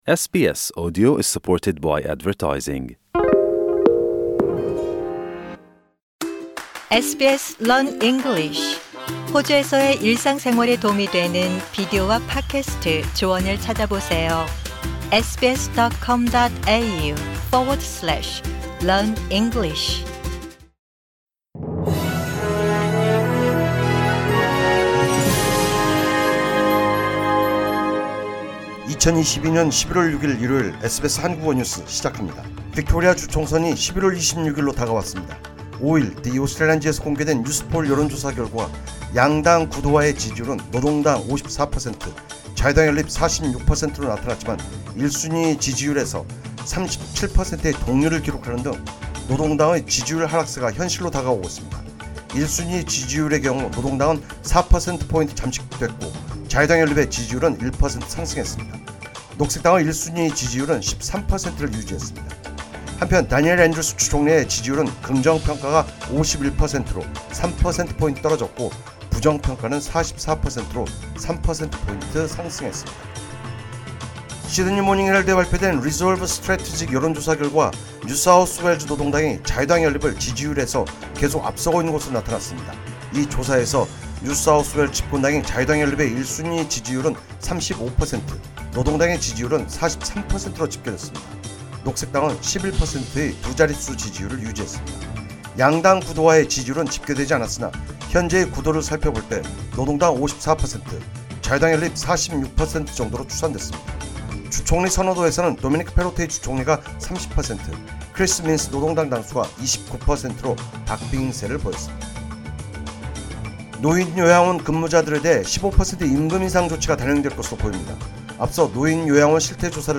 SBS Korean News on Sunday, 6 November 2022.